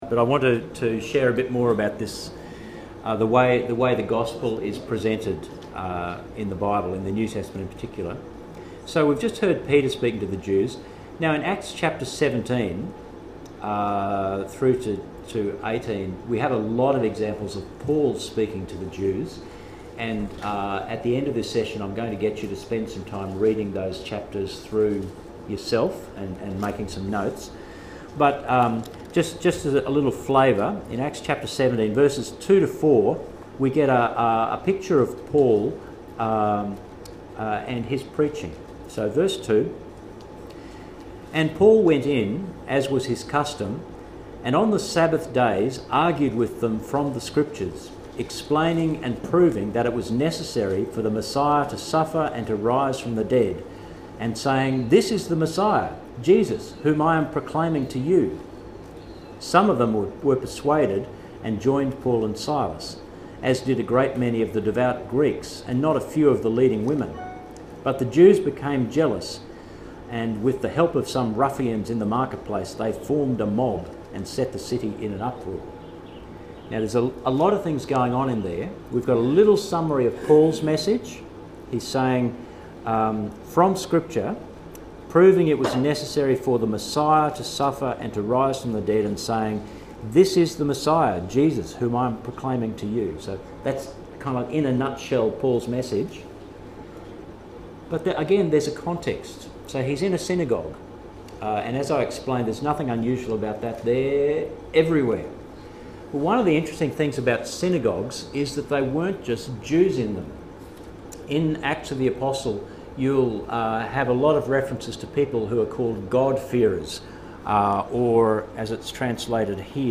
Recordings from the first Lay Preachers training